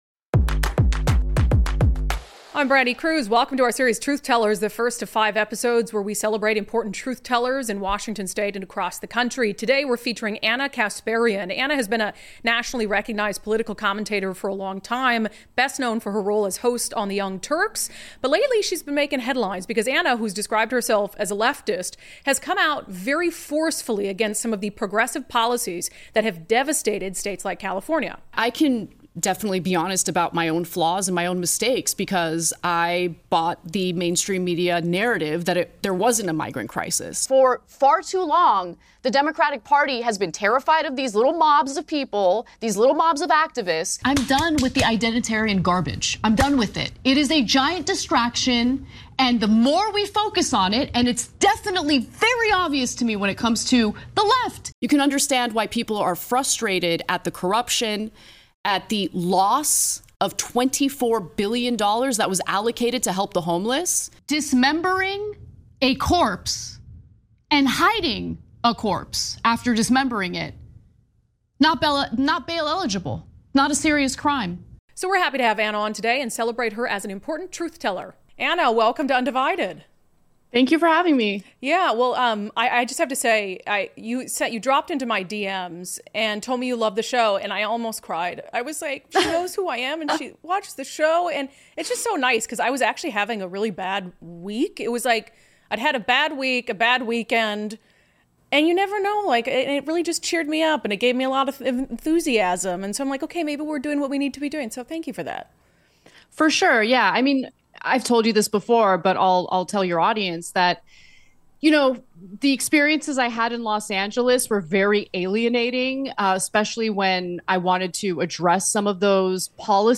Headliner Embed Embed code See more options Share Facebook X Subscribe unDivided brings you a special interview series, celebrating important "Truth Tellers" from all sides of politics and culture. Ana Kasparian is executive producer and host of The Young Turks. A self-professed Leftist, she’s become increasingly critical of the Democratic Party and its policies – especially in places like California.